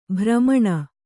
♪ bhramaṇa